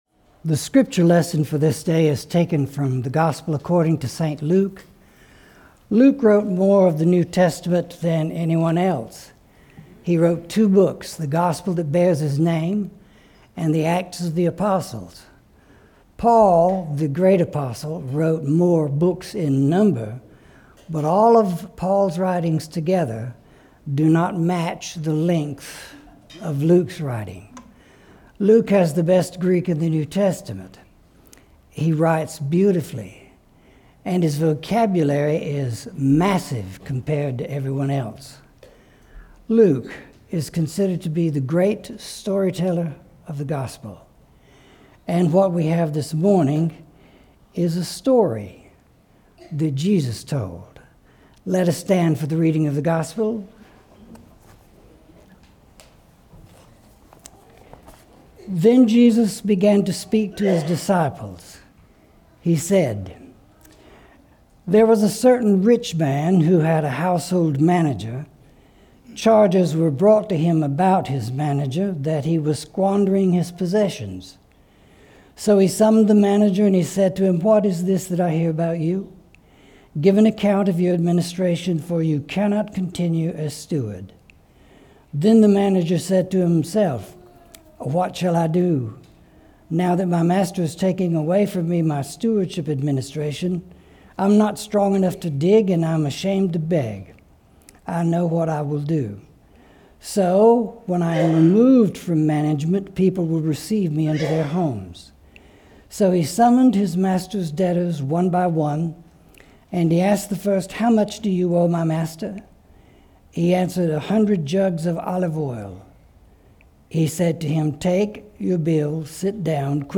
September 21, 2025 Sermon Audio